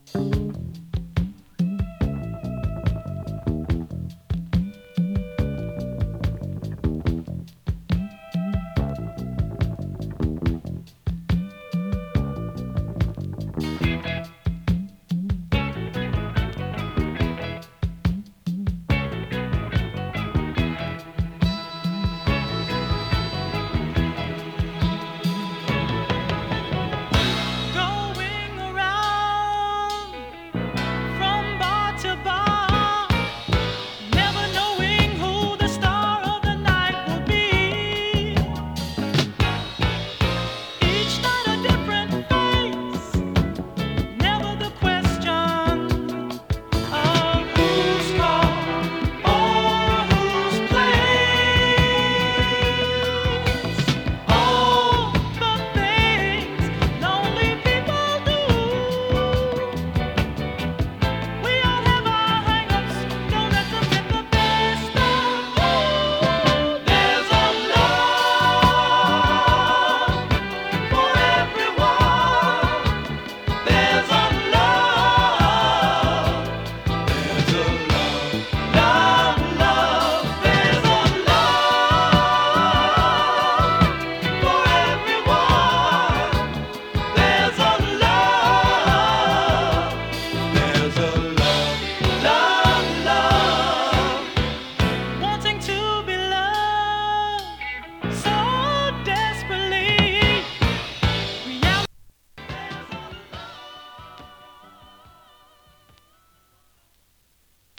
フィリーソウル
多彩なコーラス・ワークがすばらしいスウイート/フィリーソウル！